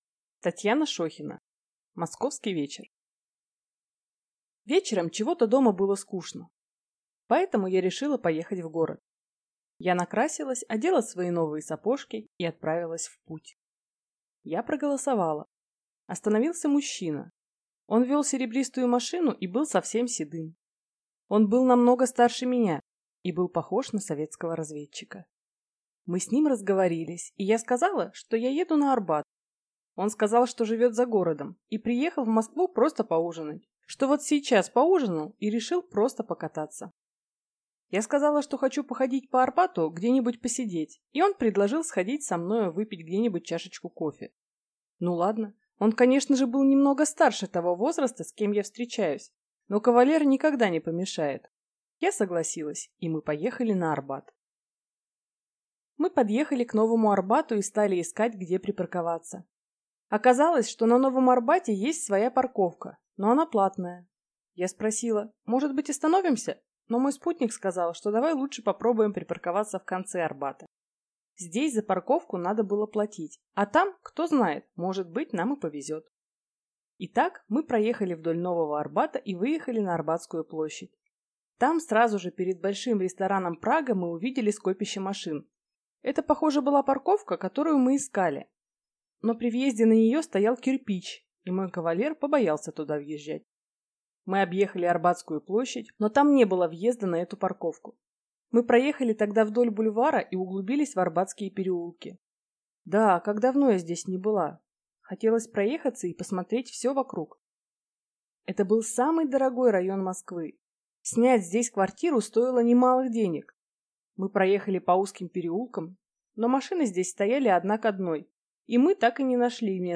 Аудиокнига Московский вечер | Библиотека аудиокниг